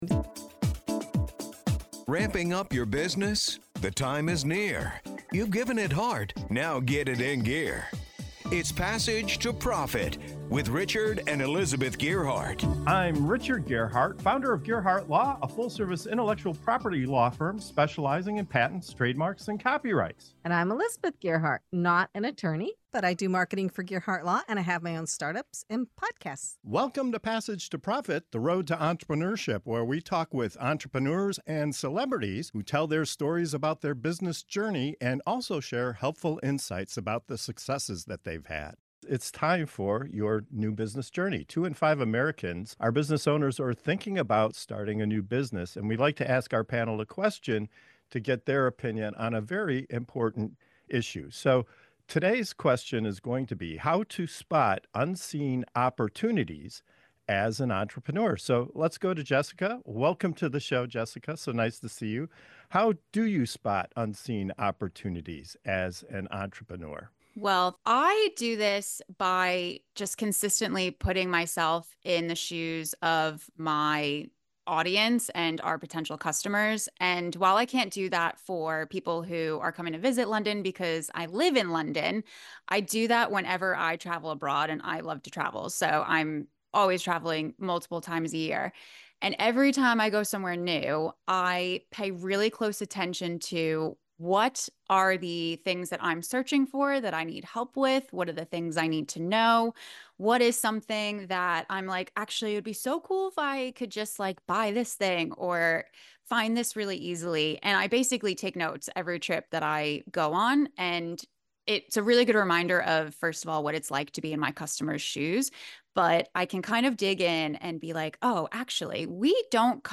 How do successful entrepreneurs uncover the hidden gems that spark great business ideas? In this segment of "Your New Business Journey" on Passage to Profit Show, our panel dives into the art of spotting unseen opportunities—whether it's stepping into your customers’ shoes, tuning into intuition, harnessing the power of questions, or staying endlessly curious.